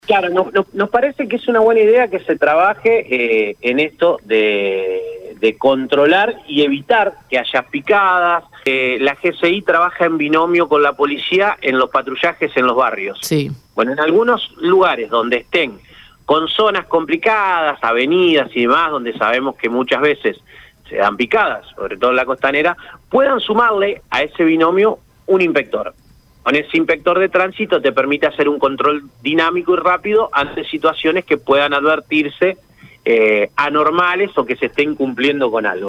El concejal santafesino Ignacio «Nacho» Laurenti, representante de Vida y Familia, participó en una entrevista en Radio EME donde abordó varios temas de interés para la ciudad en el contexto de la reciente aprobación del Presupuesto 2025.
CONCEJAL-IGNACIO-LAURENTI-LLA-SOBRE-BRIGADA-ANTIPICADAS.mp3